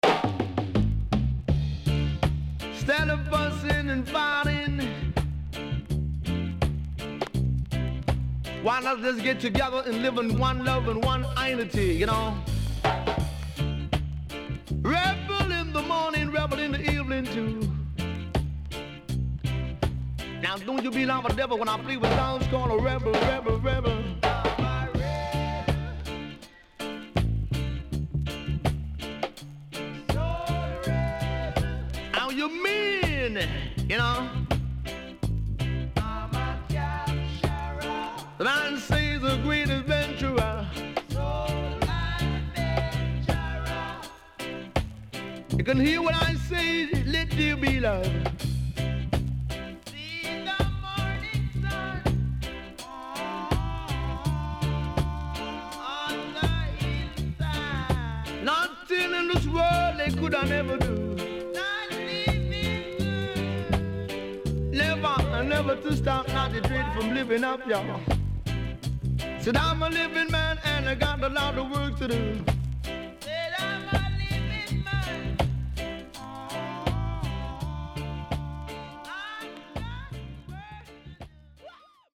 HOME > LP [VINTAGE]  >  70’s DEEJAY
SIDE A:所々チリノイズがあり、少しプチノイズ入ります。